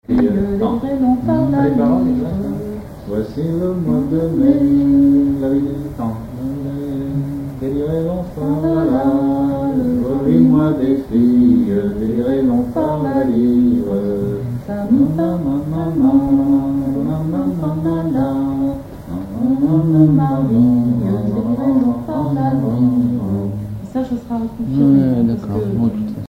Genre laisse
Chansons maritimes
Pièce musicale inédite